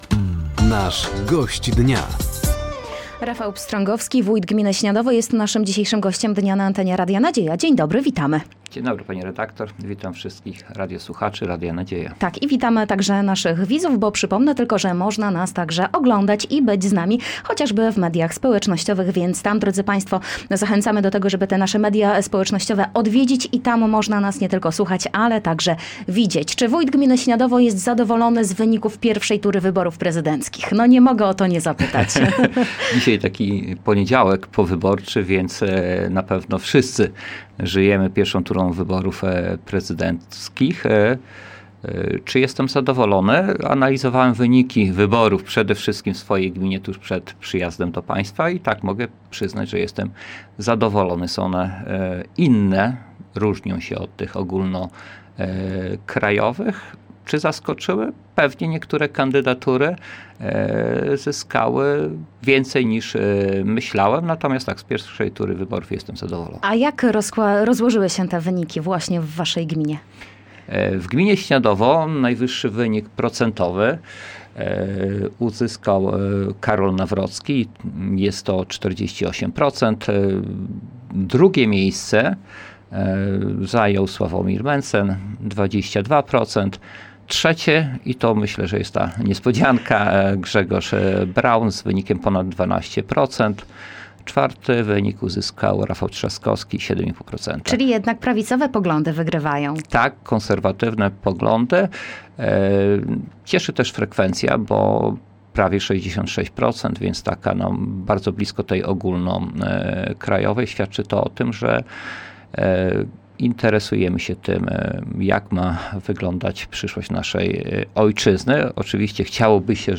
Studio Radia Nadzieja odwiedził Rafał Pstrągowski, wójt gminy Śniadowo.